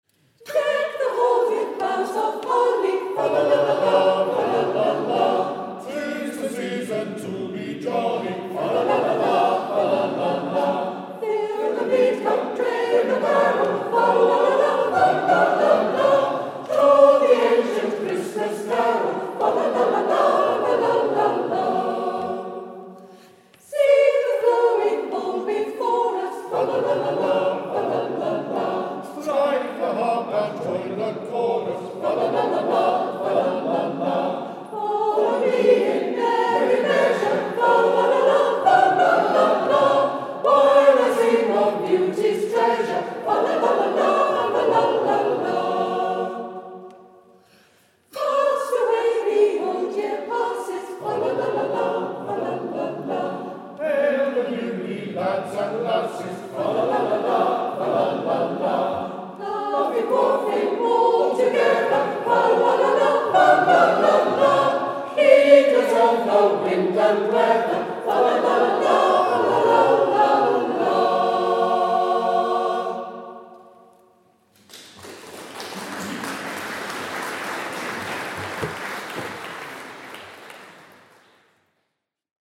Holy Trinity Church in Minchinhampton looked wonderfully festive for our Christmas concert this year.
The audience did a fine job of joining in with a number of favourite carols too.
organ